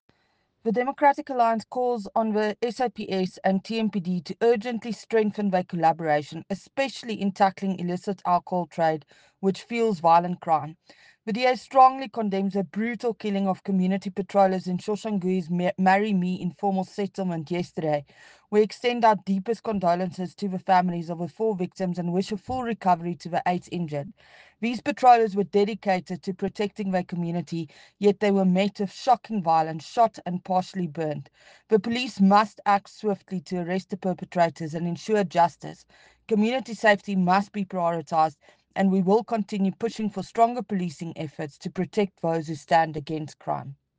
Note to Editors: Please find the attached soundbites in